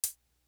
Professional Hat.wav